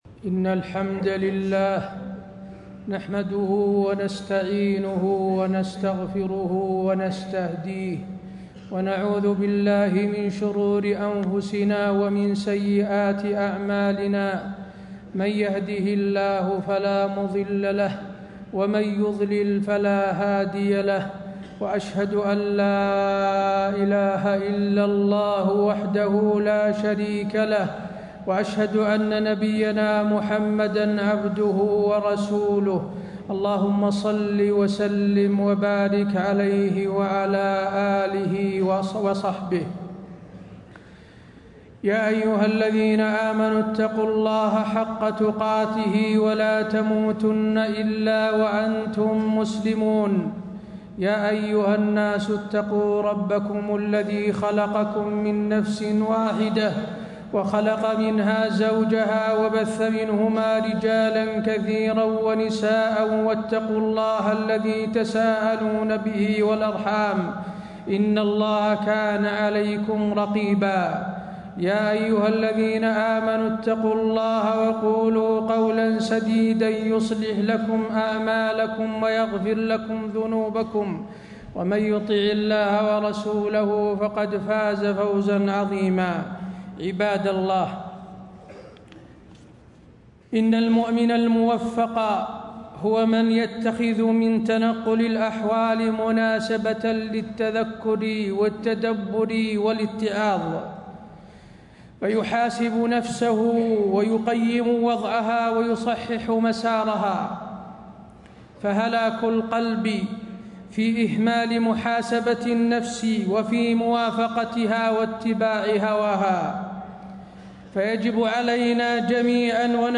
تاريخ النشر ٣٠ ذو الحجة ١٤٣٥ هـ المكان: المسجد النبوي الشيخ: فضيلة الشيخ د. حسين بن عبدالعزيز آل الشيخ فضيلة الشيخ د. حسين بن عبدالعزيز آل الشيخ محاسبة النفس على أعتاب عام جديد